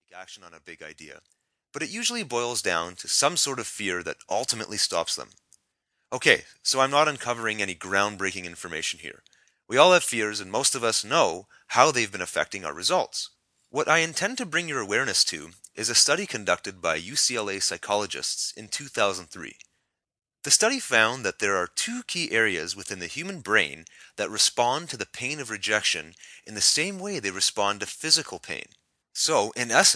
Totally amazing mp3, this five minute mp3 will benefit you as the speaker conveys empathy for those of you have experienced rejection at some point in their life. This powerful mp3 talks you through your feelings of rejection.